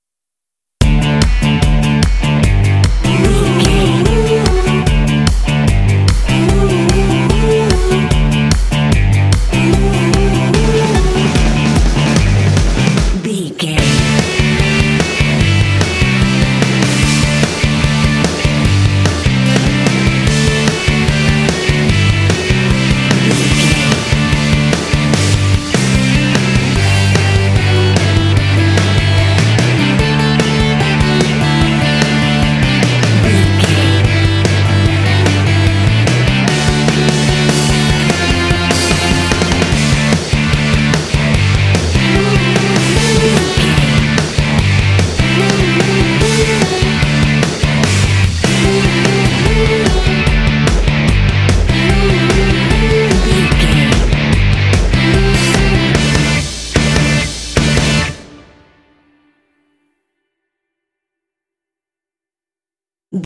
This cute indie track is ideal for action and sports games.
Uplifting
Ionian/Major
D
Fast
happy
energetic
electric guitar
bass guitar
drums
vocals
classic rock
alternative rock